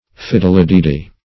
fiddledeedee - definition of fiddledeedee - synonyms, pronunciation, spelling from Free Dictionary